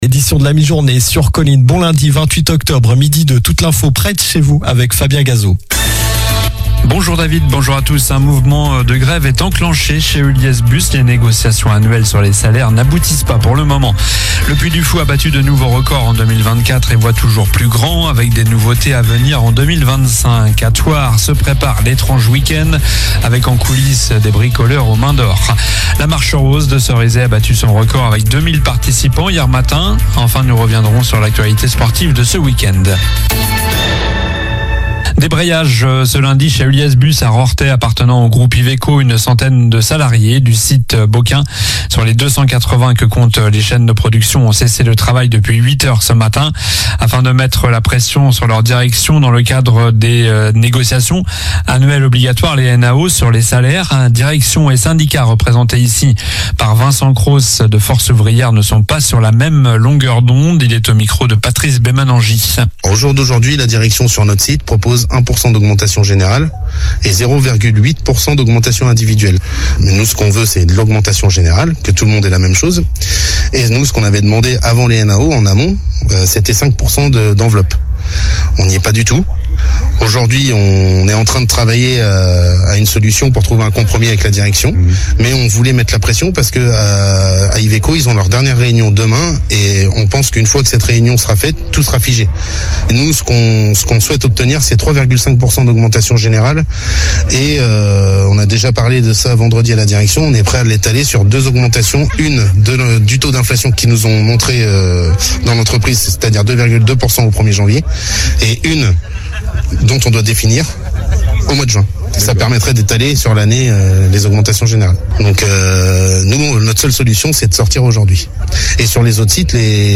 Journal du lundi 28 octobre (midi)